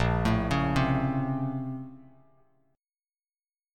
A#mM9 chord